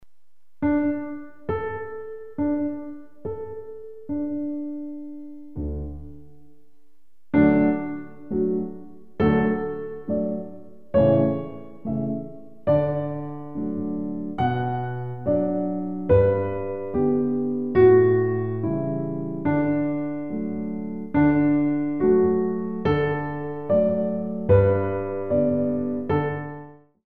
All piano CD for Pre- Ballet classes.
2/4 Accent 1